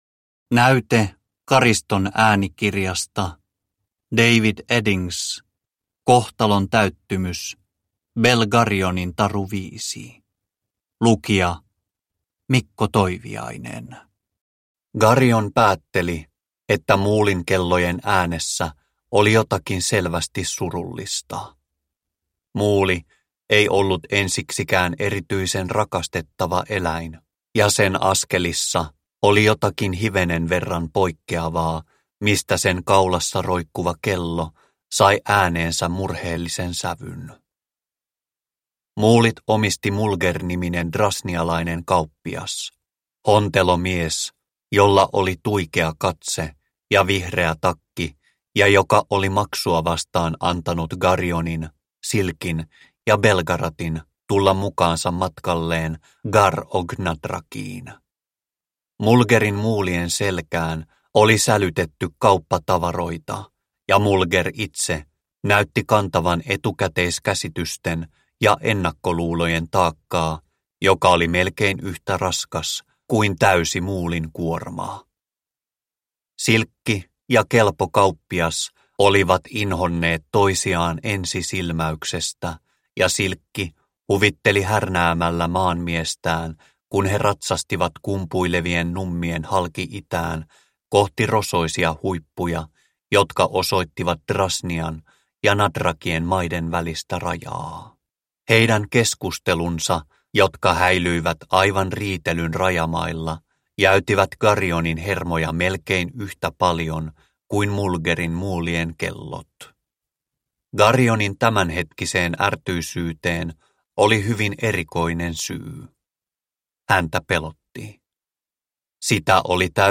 Kohtalon täyttymys - Belgarionin taru 5 – Ljudbok – Laddas ner